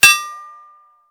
metal_04.ogg